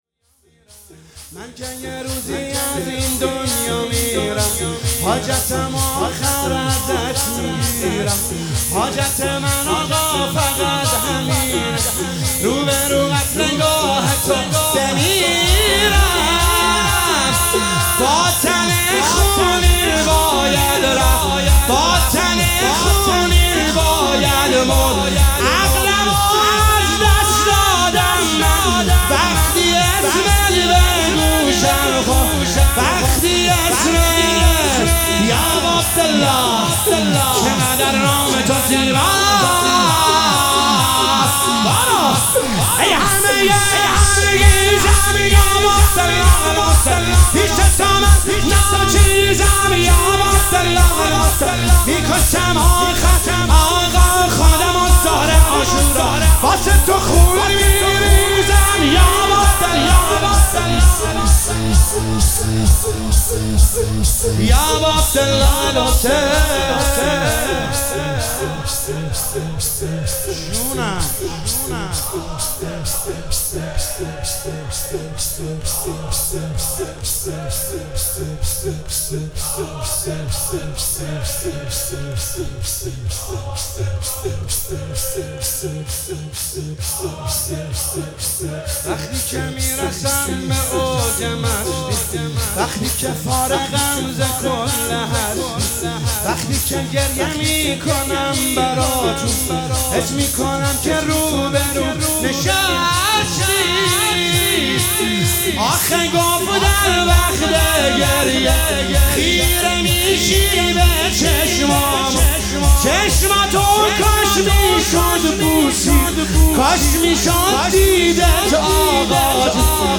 مداحی شور
هیئت بهشت عباس قم
نوحه شور شب اول ماه محرم 1403